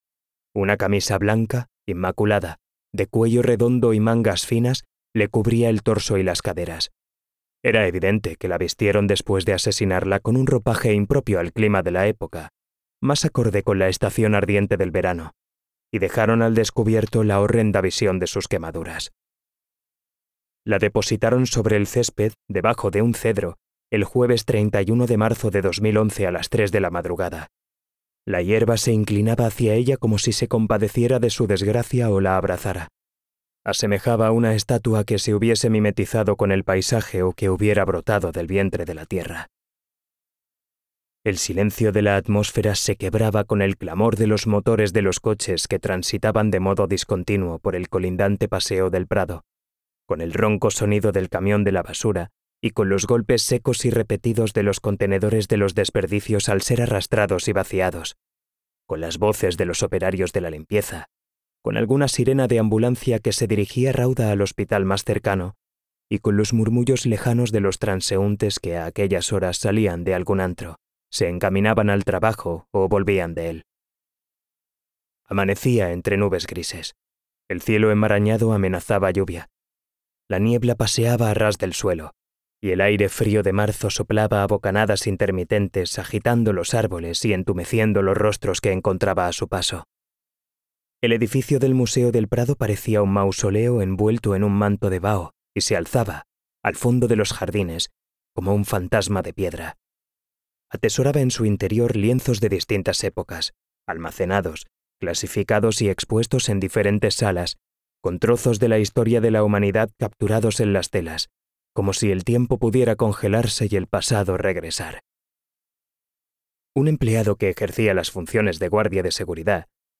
Audiolibros Novela policíaca - Sonolibro